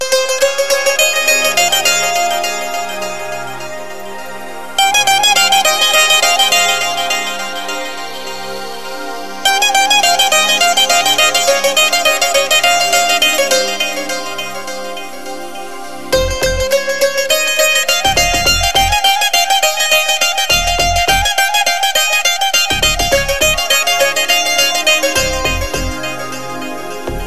Description: Guitar